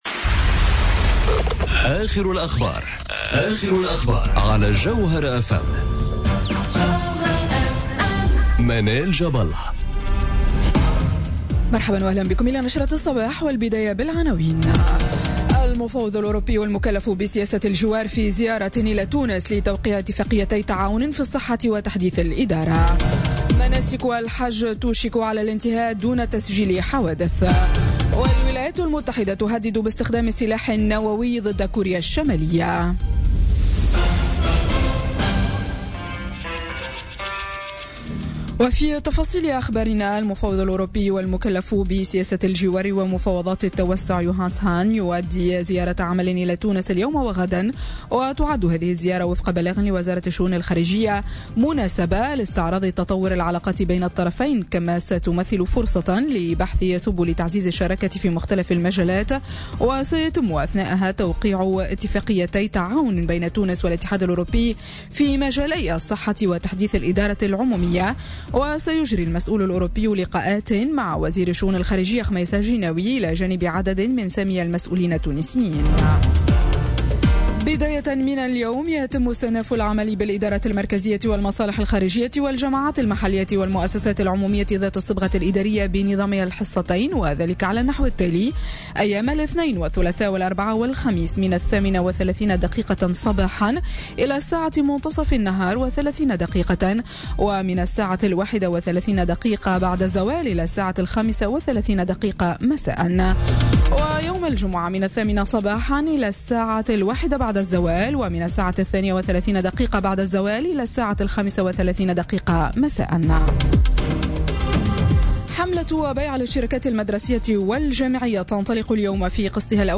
Journal Info 07h00 du lundi 4 septembre 2017